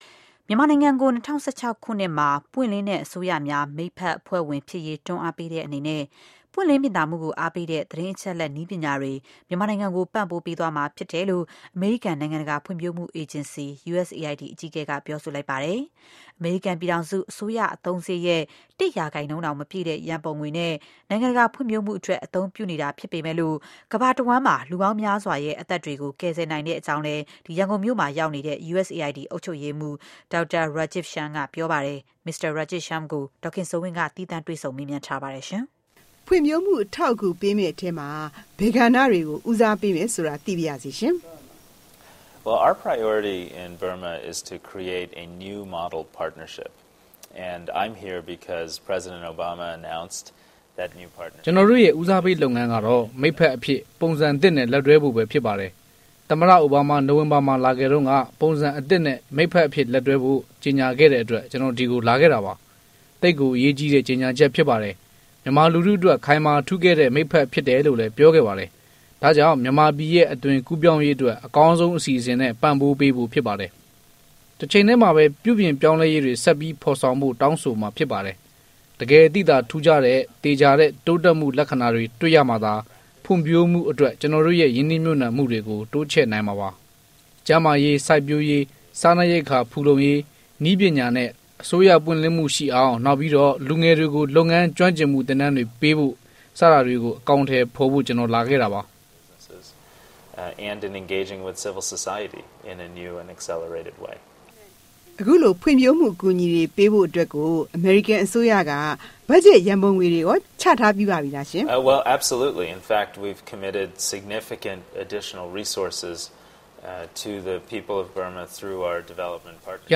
သီးသန့်တွေ့ဆုံမေးမြန်းခန်း